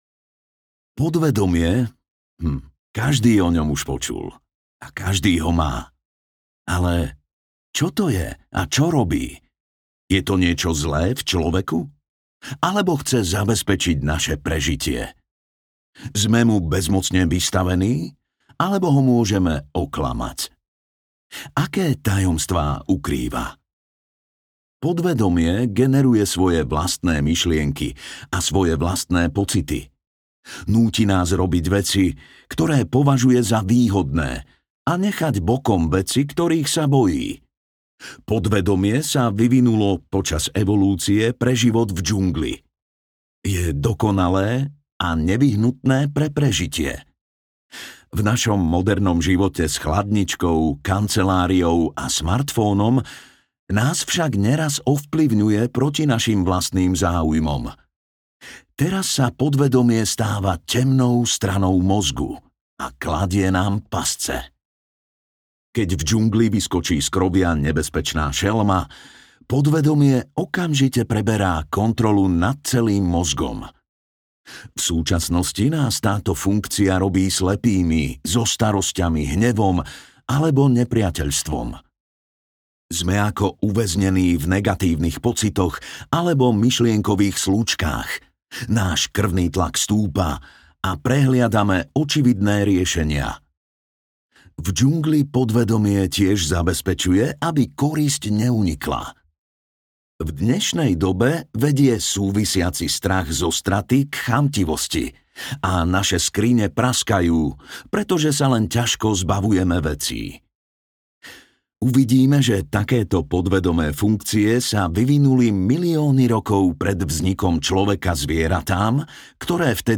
Temná strana mozgu audiokniha
Ukázka z knihy